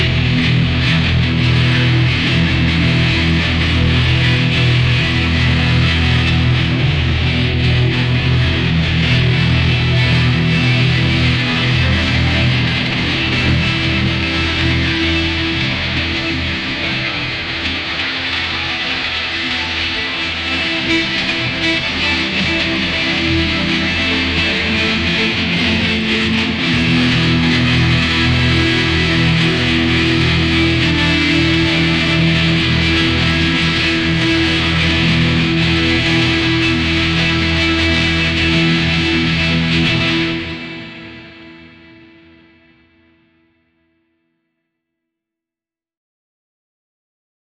Here is a simple workflow generating some cello music: Stable Audio workflow
The workflow produced the following audio (sound warning, it is loud!):
As you can tell its mostly noise and doesn’t sound very good.
metal_cello.flac